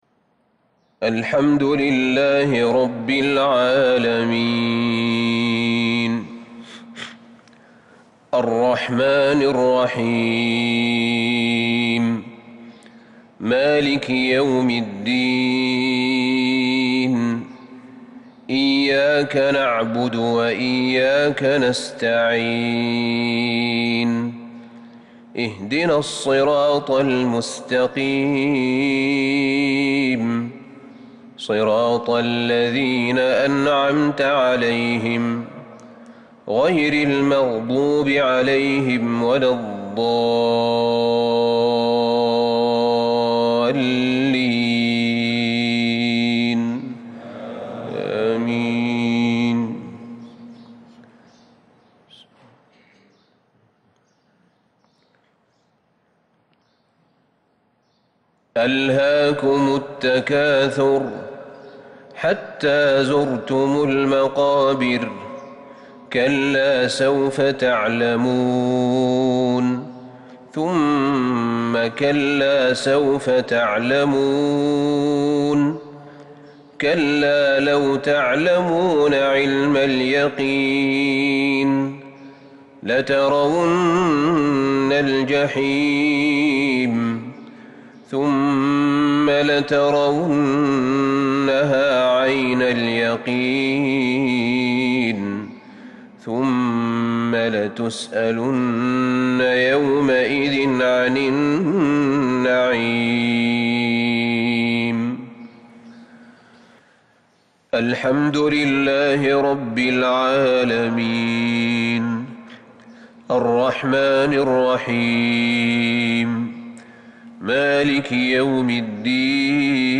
مغرب الأحد 4-6-1442هـ من سورتي التكاثر والماعون | maghrib Prayer from Surat al-Takathur and AlMa'un /17/1/2021 > 1442 🕌 > الفروض - تلاوات الحرمين